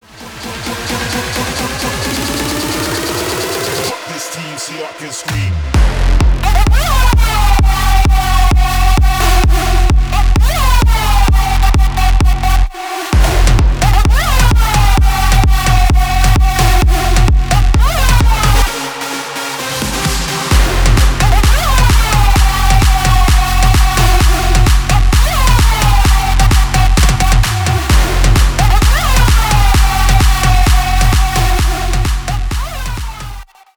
Электроника # без слов